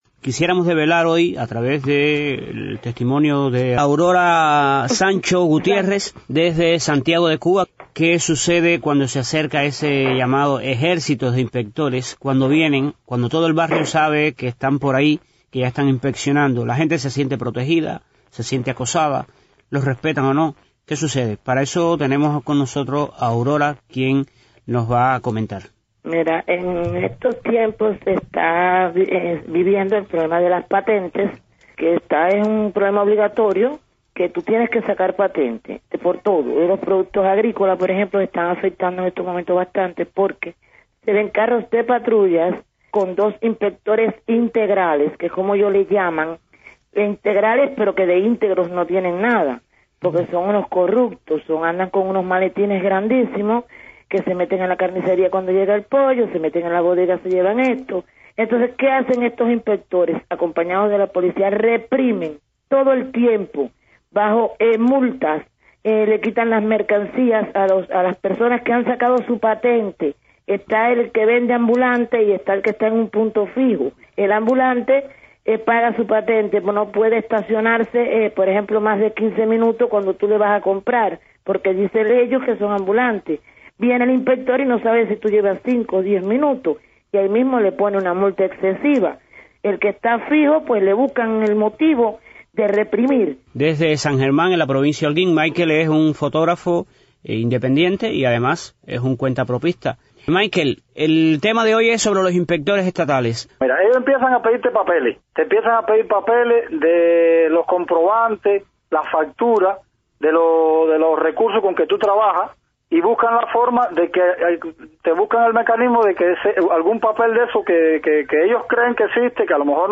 hablaron con varios cuentapropistas de la isla para conocer sus experiencias sobre la labor de los inspectores gubernamentales.